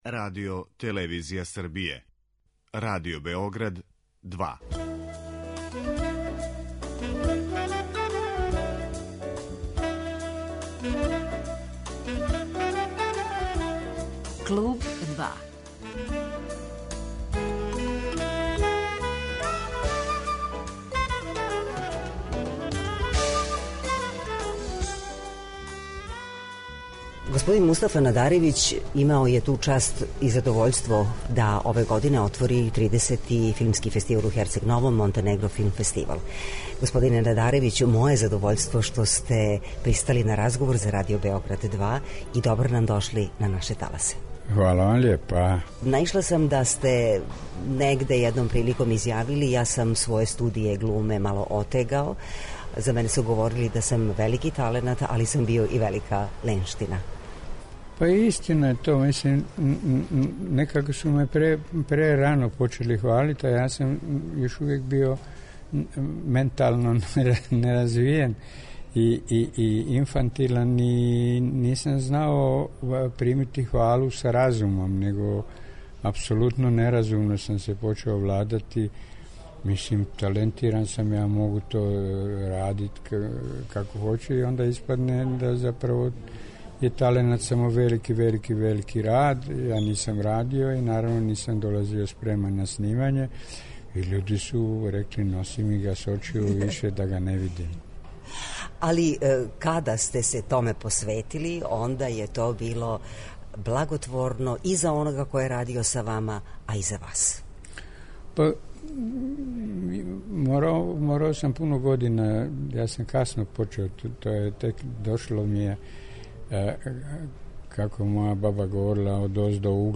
Разговор са Мустафом Надаревићем